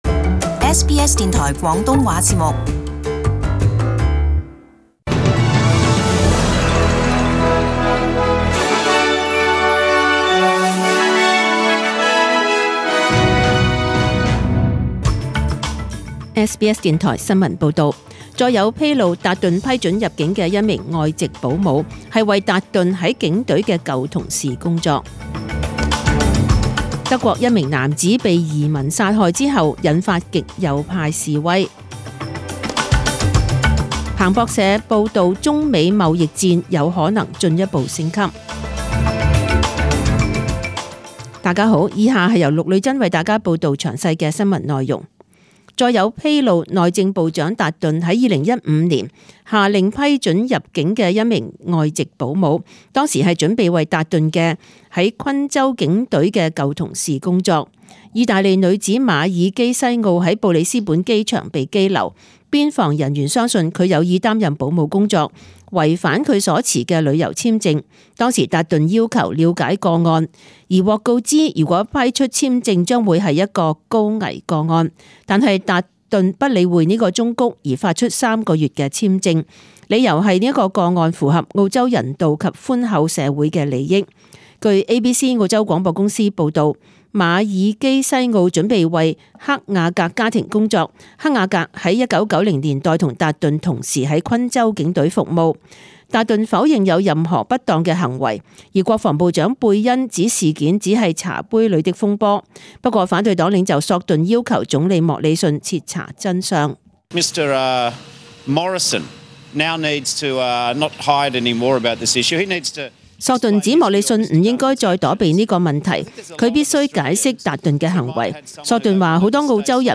请收听本台为大家准备的详尽早晨新闻。